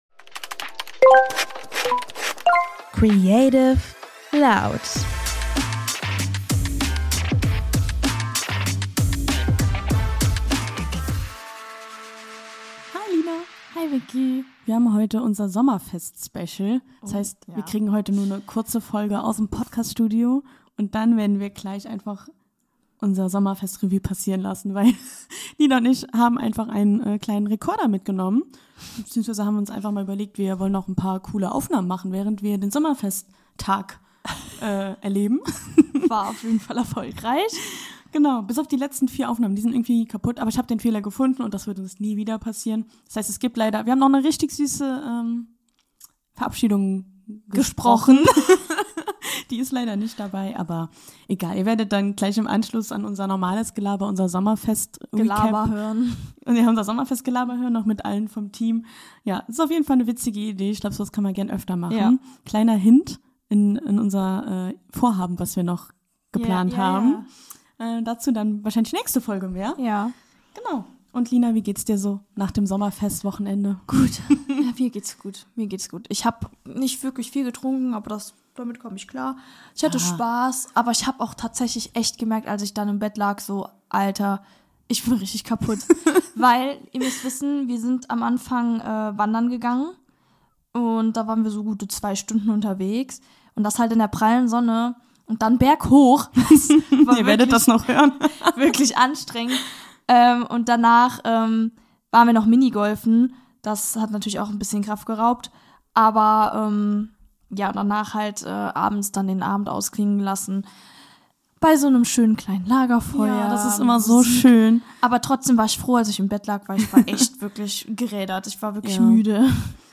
Heute entführen wir euch zu unserem Sommerfest und haben in dieser besonderen Sonderfolge einige Audio-Schnipsel von unserem Tag für euch aufgenommen. Ihr seid hautnah bei unserer Wanderung, dem Mini-Golf und dem Lagerfeuer dabei.